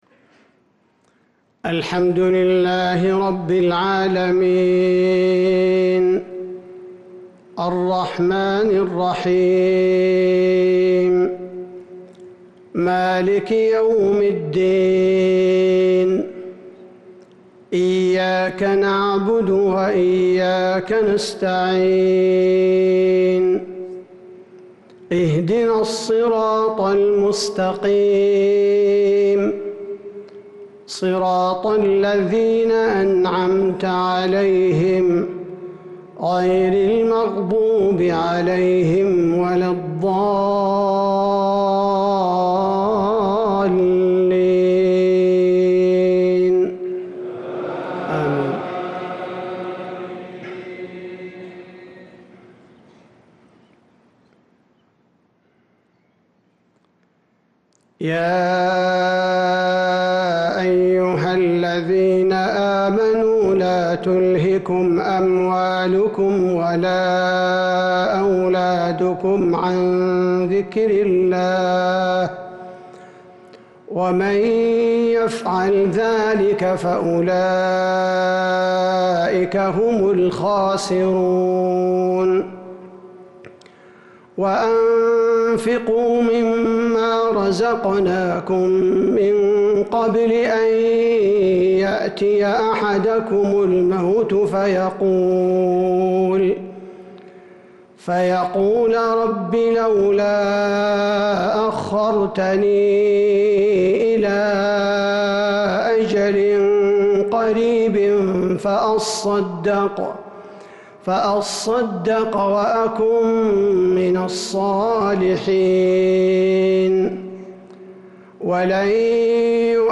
صلاة المغرب للقارئ عبدالباري الثبيتي 12 ذو القعدة 1443 هـ